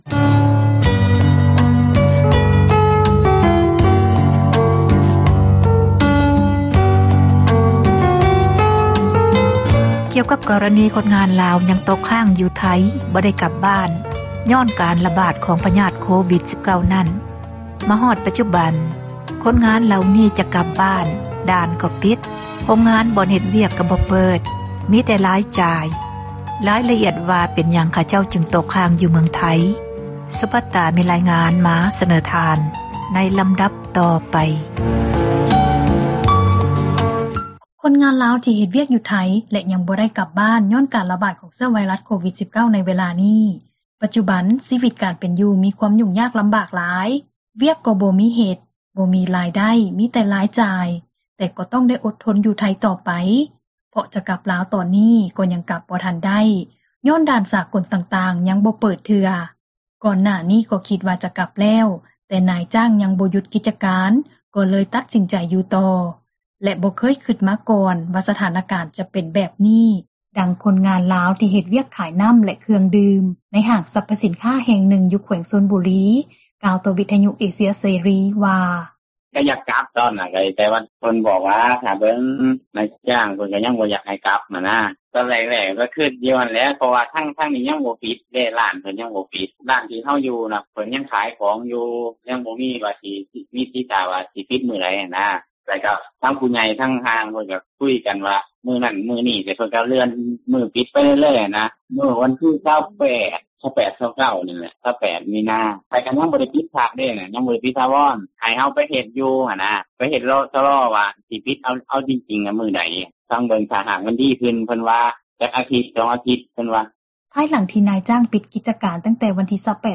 ໃນຂນະທີ່ ຄົນງານລາວ ທີ່ເຮັດວຽກຢູ່ໂຮງງານເຂົ້າໝົມ ຢູ່ແຂວງສະມຸດປາການ ຄົນນຶ່ງ ກໍກ່າວວ່າ: